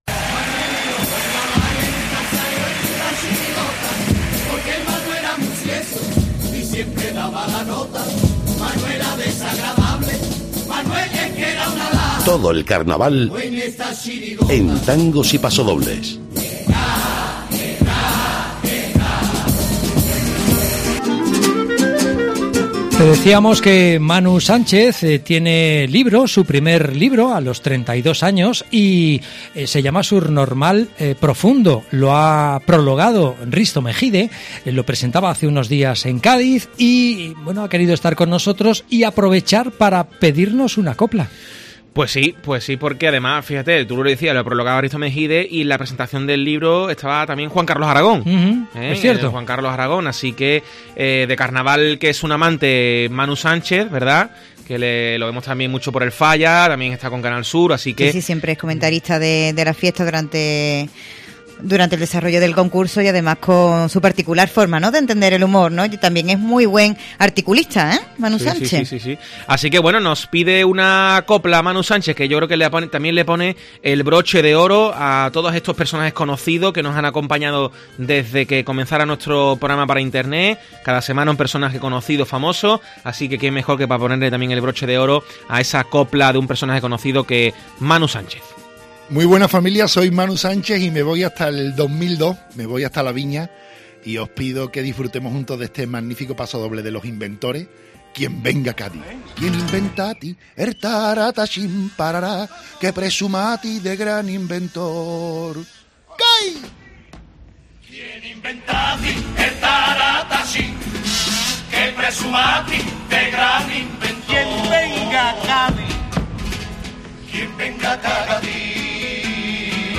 AUDIO: El humorista y presentador sevillano nos deja su copla favorita de Carnaval en Tangos y Pasodobles